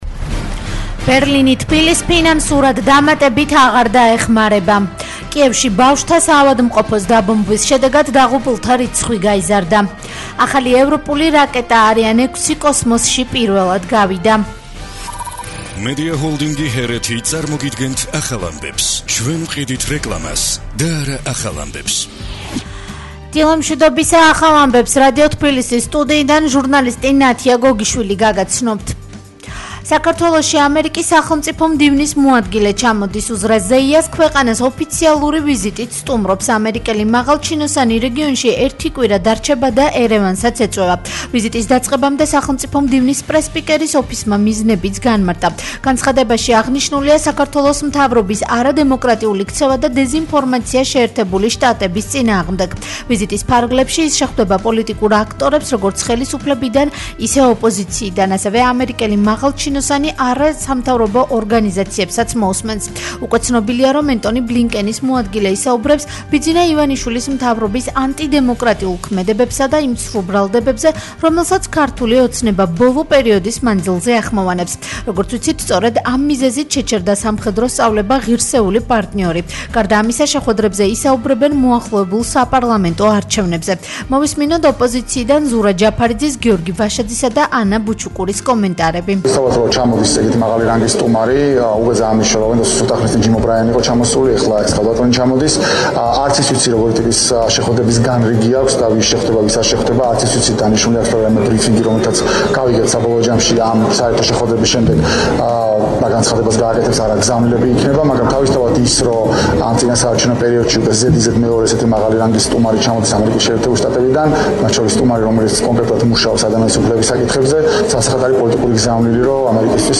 ახალი ამბები 10:00 საათზე